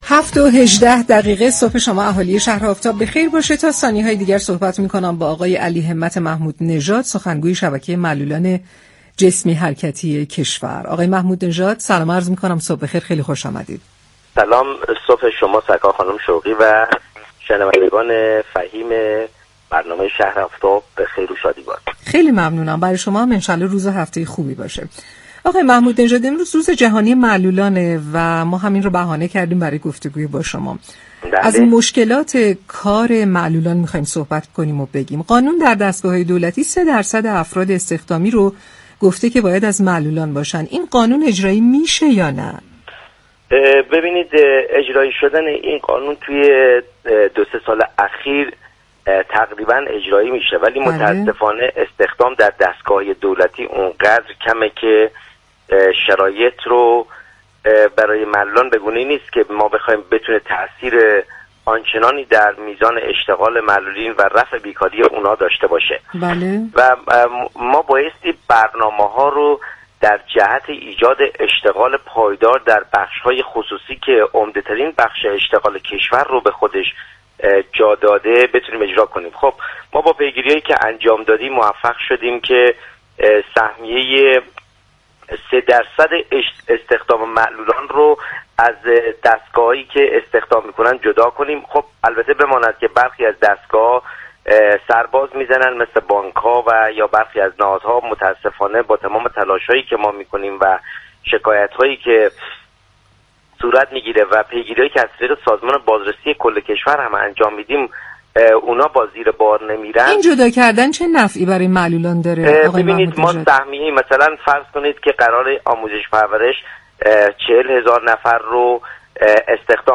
در گفت و گو با شهر آفتاب رادیو تهران